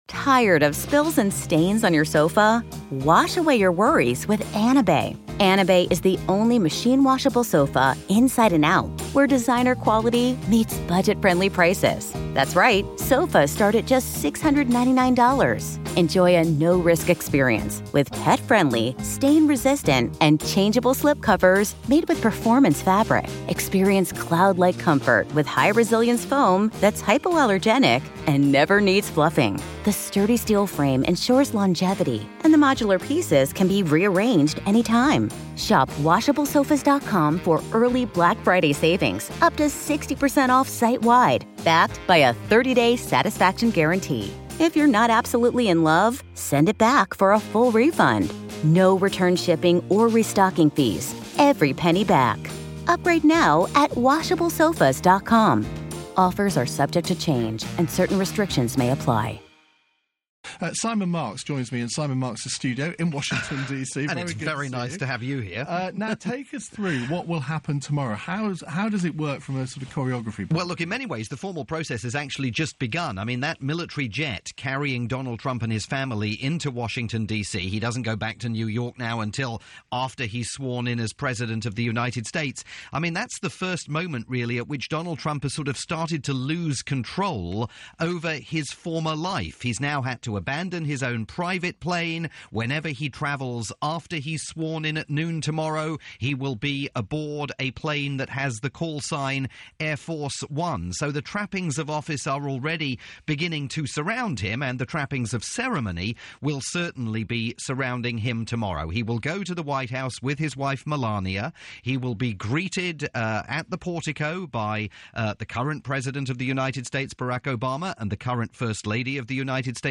Donald Trump has arrived in Washington for his inauguration as America's 45th President. The ceremony takes place on Friday.